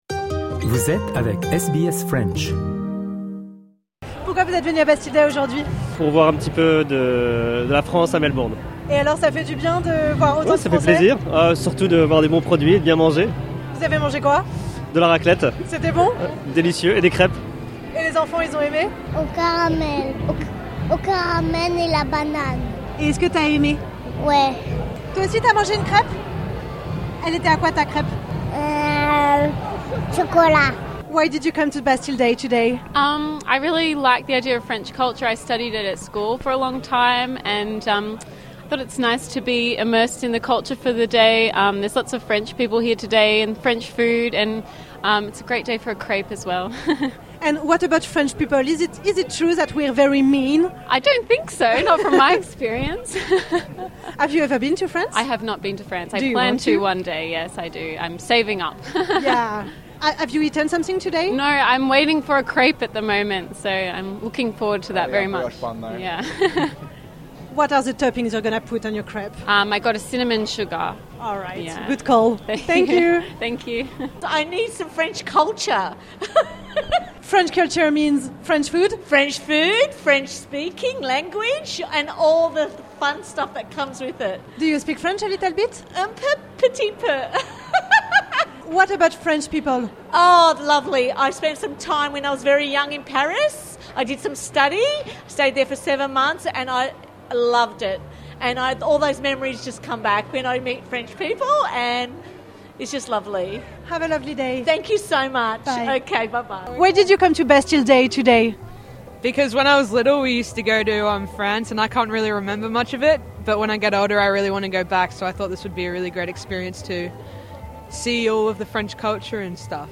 A l’occasion du Bastille Day de Melbourne, nous avons rencontré les festivaliers, petits et grands, Français et Australiens, en quête de culture française.